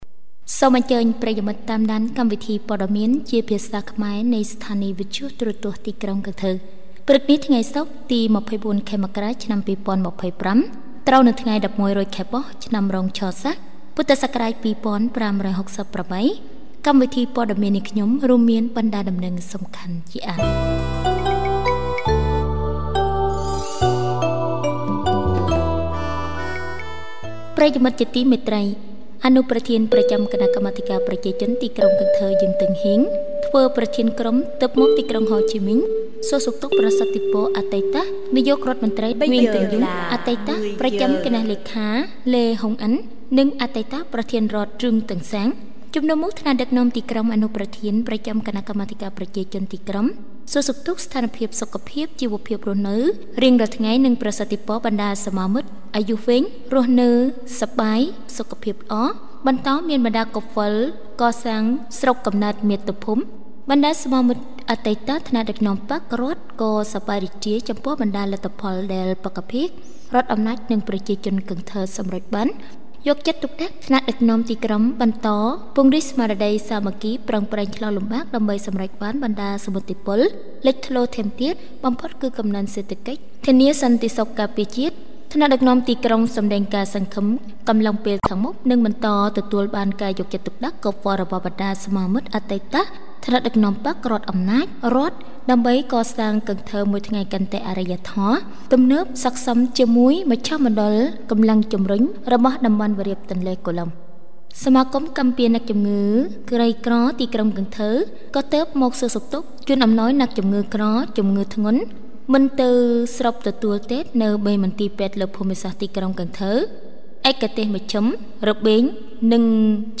Bản tin tiếng Khmer sáng 24/1/2025